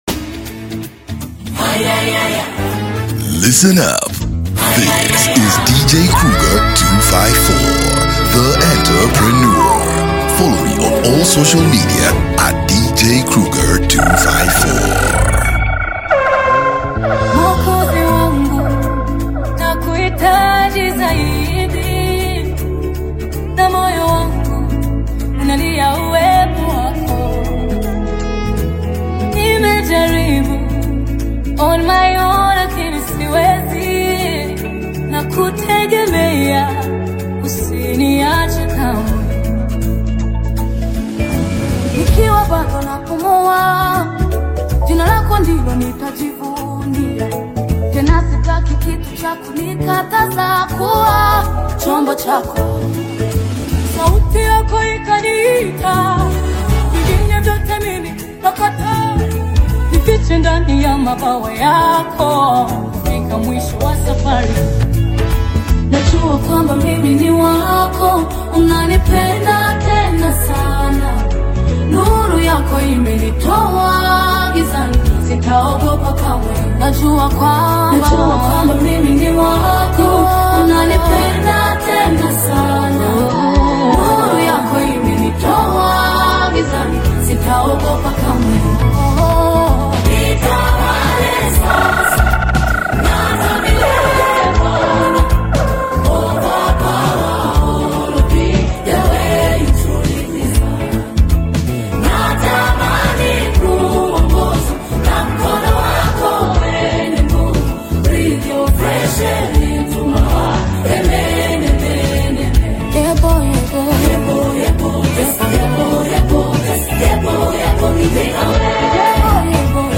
Non-Stop Mix